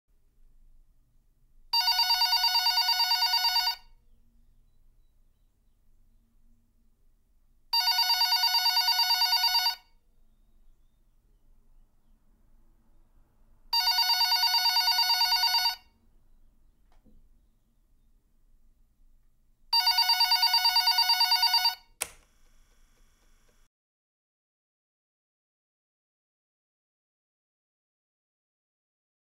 دانلود صدای زنگ تلفن اداری از ساعد نیوز با لینک مستقیم و کیفیت بالا
جلوه های صوتی